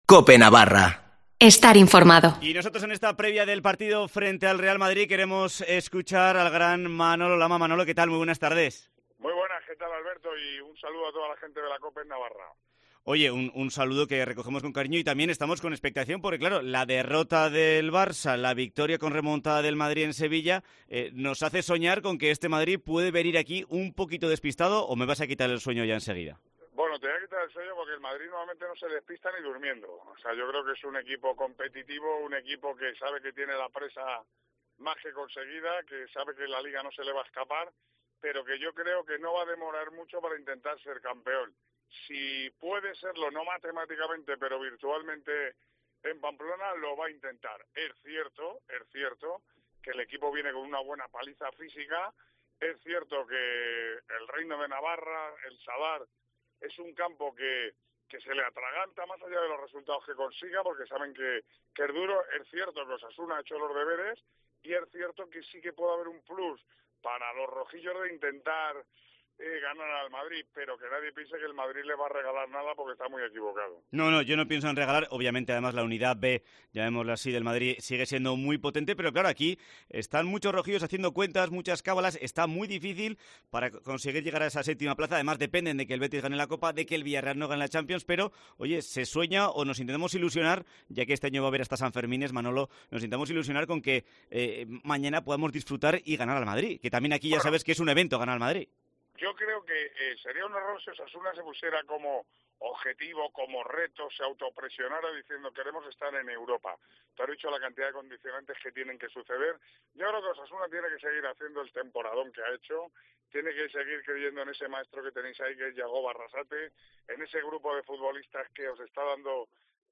Entrevista con Manolo Lama